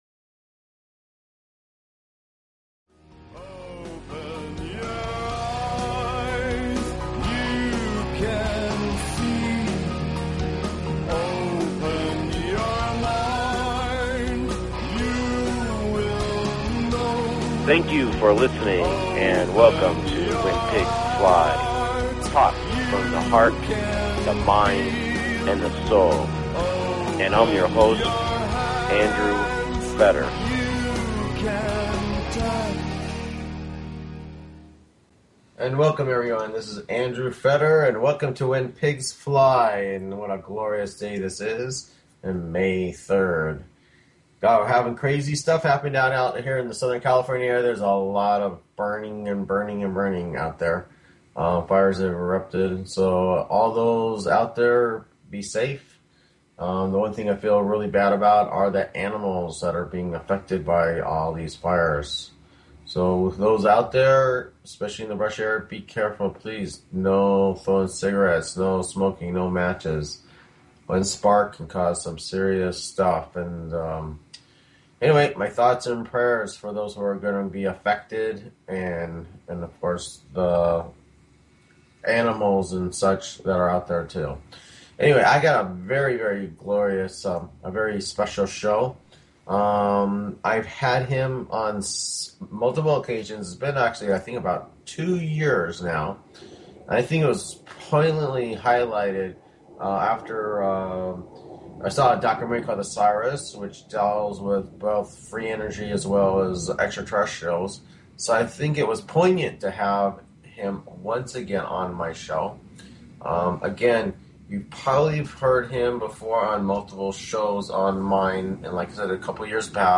Talk Show Episode, Audio Podcast, When_Pigs_Fly and Courtesy of BBS Radio on , show guests , about , categorized as
This interview will be an update of his sightings as well as important information that his contact, Abena a female captain and pilot of the Oxicarian Orion race requests for him to disclose.